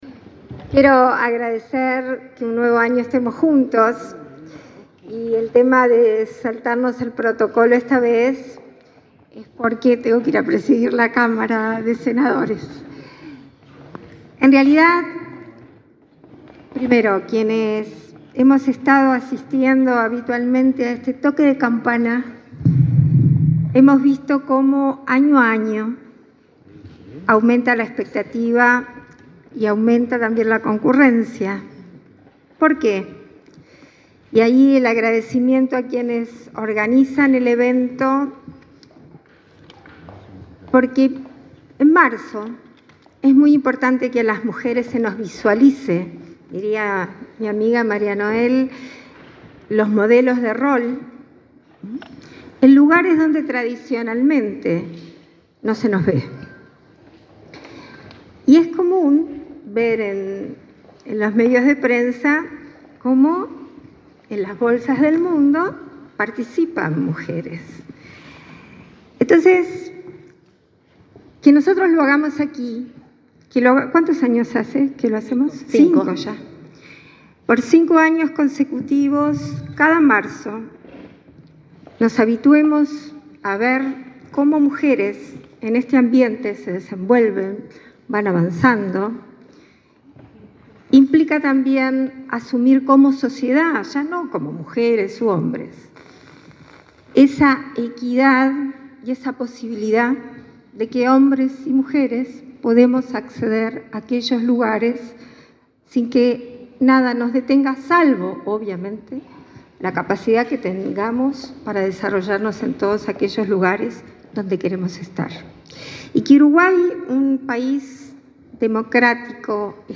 Palabras de la vicepresidenta, Beatriz Argimón
Palabras de la vicepresidenta, Beatriz Argimón 05/03/2024 Compartir Facebook X Copiar enlace WhatsApp LinkedIn La vicepresidenta de la República, Beatriz Argimón, hizo uso de la palabra, este martes 5 en la Bolsa de Valores de Montevideo, donde el presidente Luis Lacalle Pou participó en el Toque de Campana por la Igualdad de Género.